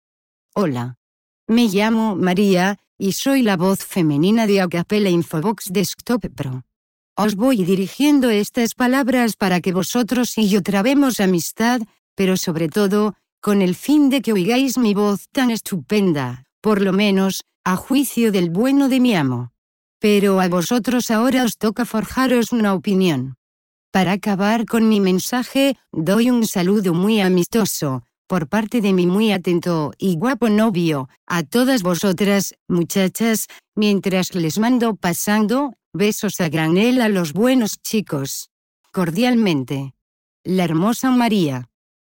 Texte De Démonstration Lu Par María, voix féminine espagnole d'Acapela Infovox Desktop Pro
Écouter la démonstration de Maria, voix féminine espagnole d'Acapela Infovox Desktop Pro